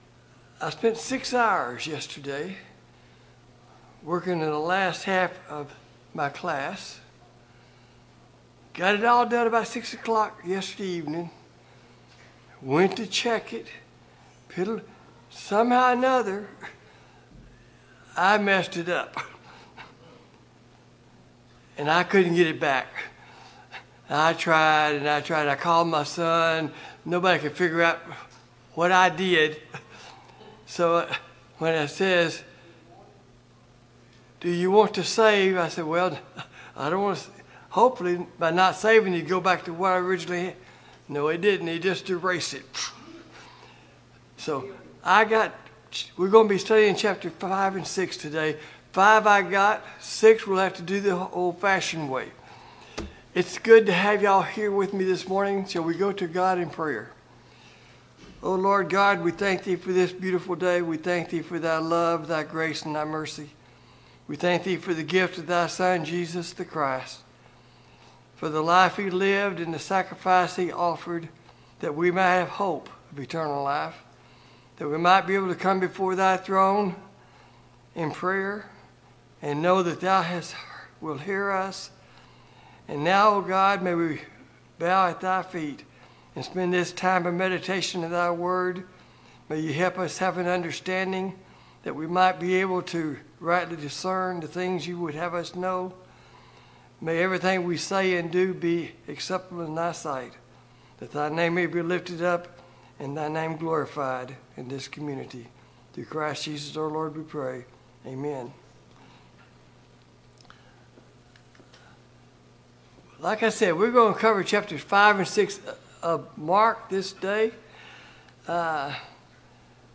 Passage: Mark 5-6 Service Type: Sunday Morning Bible Class